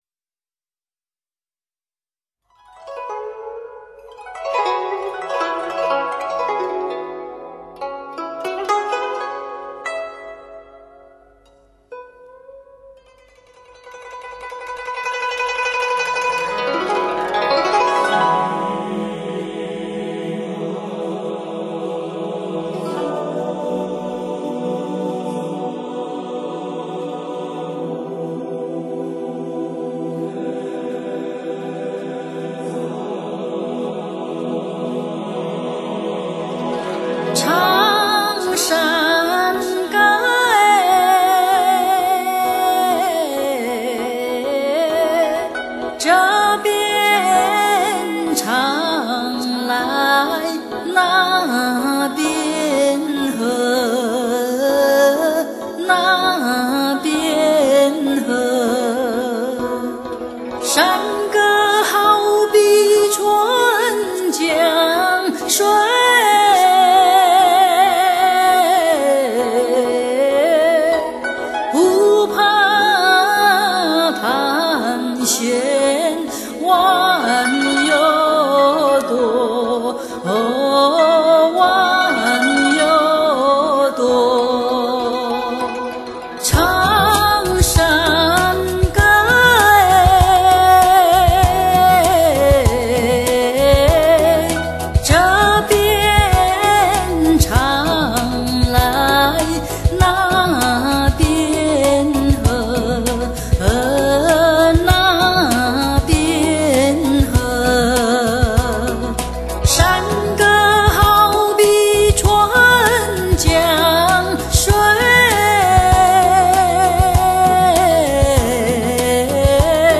录音室：成都时代飞阳声波强力录音室
【广西民歌】